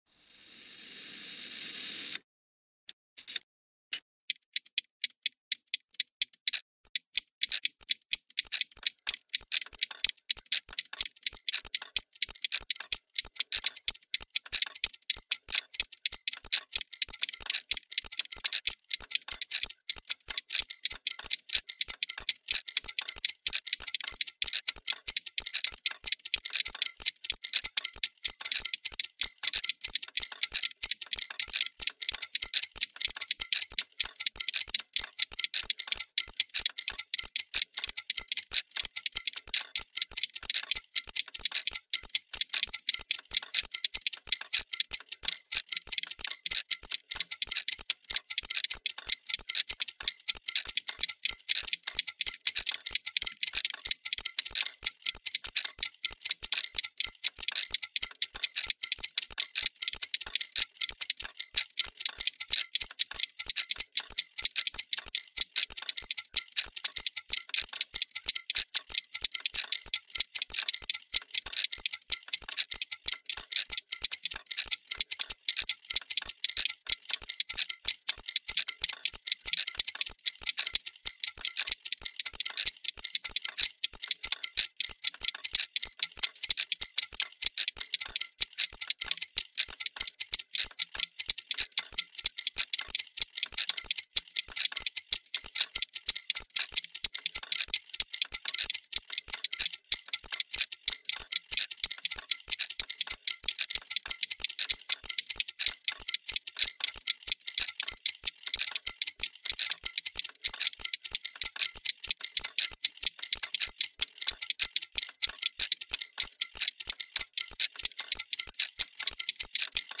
Sound Installation / Instalacija zvuka
Ten sound recordings of defected mechanical clockworks set in the sound system “The gravity of sound” artistically examine whether harmony and rhythmical harmonics are actually an individual matter?
Deset snimaka otkucaja pokvarenih mehaničkih satova postavljenih u zvučnoj instalaciji „Gravitacija zvuka“ umjetnički istražuju da li je sklad i harmonija ritma zapravo individualna stvar?
0b948-gravitacija_zvuka_mono.mp3